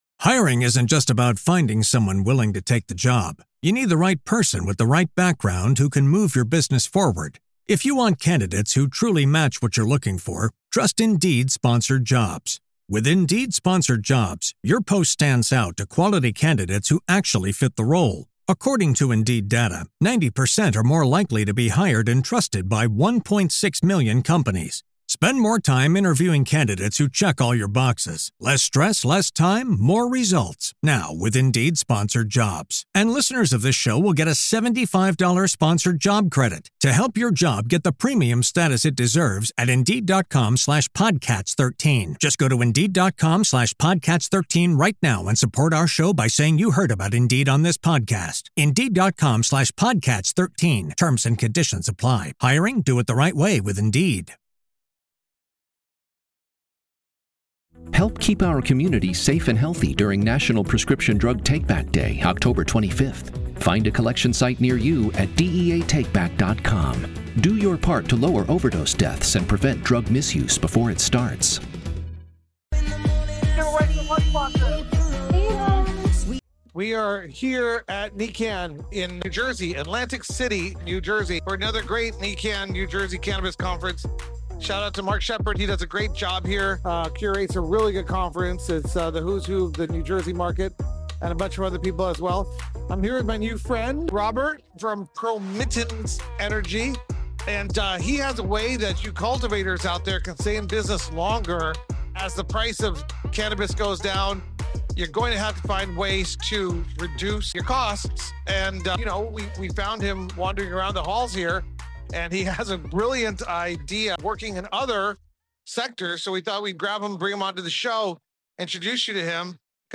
As one of the biggest cannabis conferences in the United States, NECANN’s New Jersey show gave us the chance to explore one of the hottest new cannabis markets on the East Coast while learning how clean energy intersects with the industry’s growth.